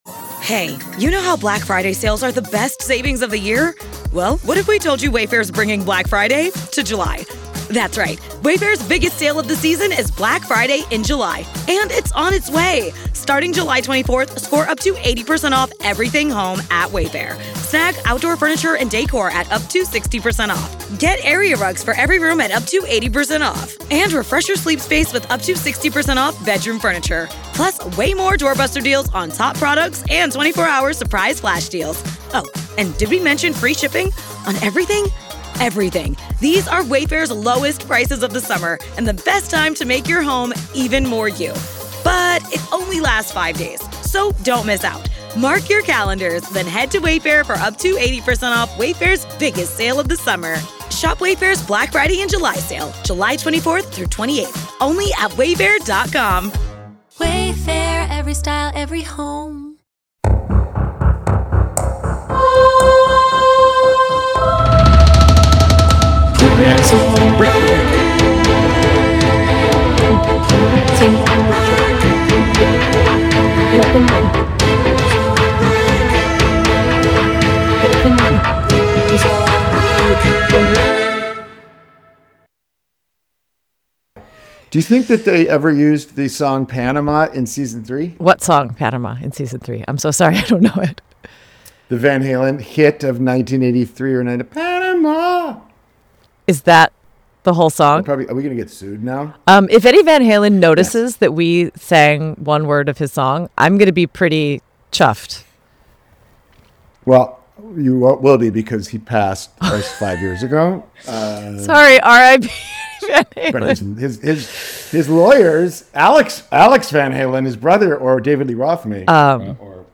NOTE: We had a lot of technical issues recording this episode. Paul is away shooting and we just couldn't get the equipment to cooperate and be balanced. The sound is off and we tried raising Paul's audio so it may be too low or unbalanced at times.